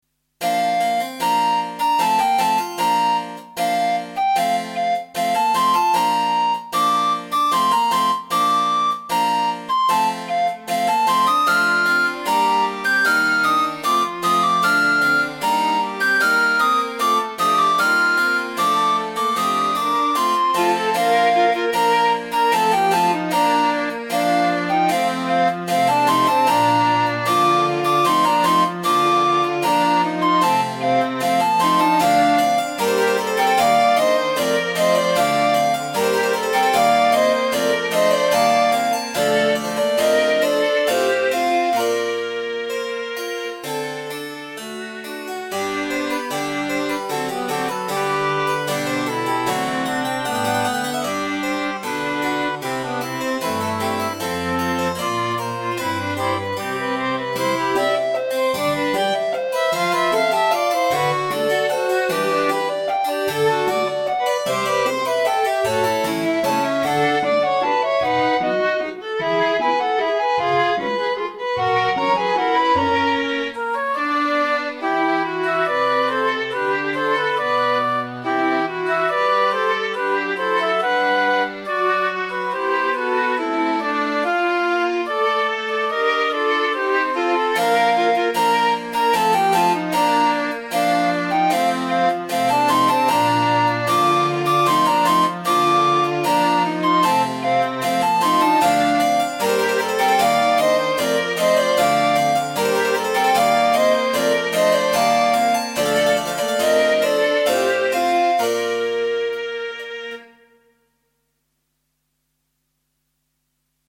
Een eenvoudige opzet van klavecimbel, fluit, cello, hobo, viool en altviool.
Ik hou van de rustieke, zondagse sfeer welke die muziek uit de recente oudheid uitstraalt.